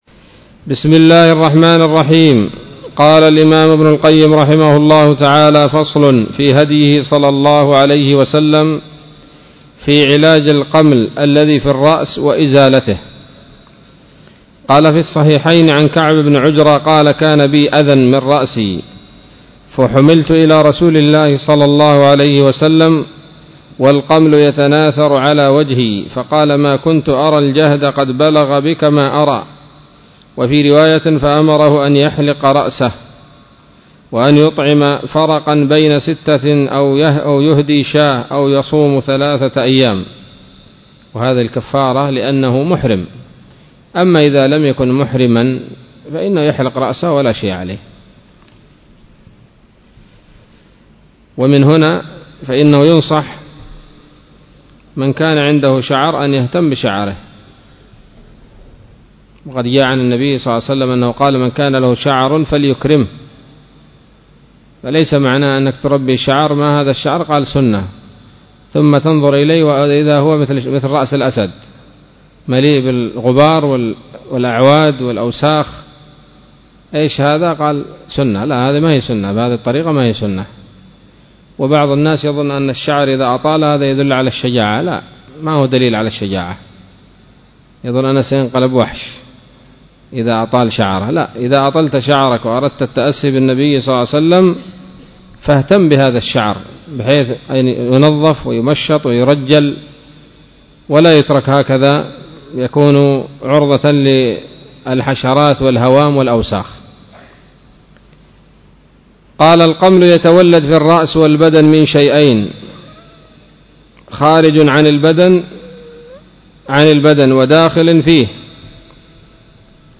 الدرس الرابع والأربعون من كتاب الطب النبوي لابن القيم